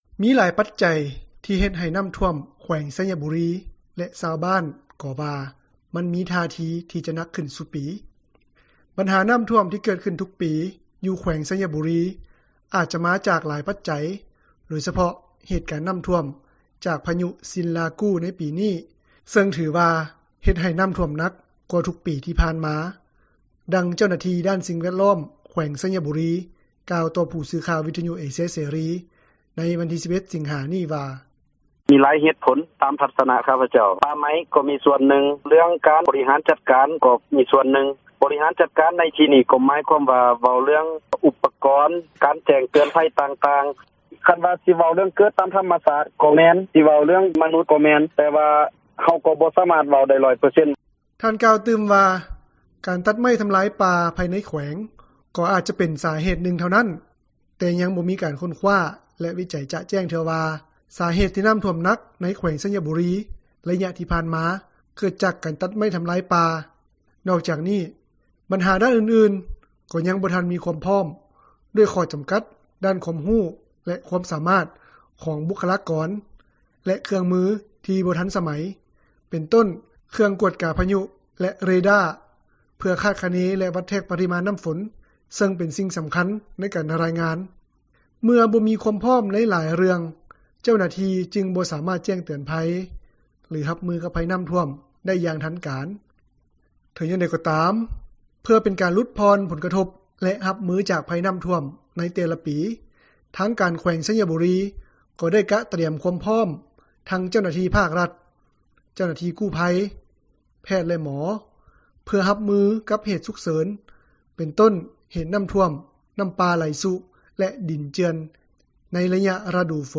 ບັນຫານ້ຳຖ້ວມ ທີ່ເກີດຂຶ້ນທຸກປີ ຢູ່ແຂວງໄຊຍະບູຣີ ອາຈມາຈາກຫຼາຍປັດຈັຍ, ໂດຍສະເພາະເຫດການນ້ຳຖ້ວມ ຈາກພະຍຸຊິນລາກູ ໃນປີນີ້, ເຊິ່ງຖືວ່າ ເຮັດໃຫ້ນ້ຳຖ້ວມໜັກກວ່າທຸກປີ ທີ່ຜ່ານມາ. ດັ່ງເຈົ້າໜ້າທີ່ ດ້ານສິ່ງແວດລ້ອມ ແຂວງໄຊຍະບູຣີ ກ່າວຕໍ່ຜູ້ສື່ຂ່າວ ວິທຍຸເອເຊັຽເສຣີ ໃນວັນທີ 11 ສິງຫາ ນີ້ວ່າ: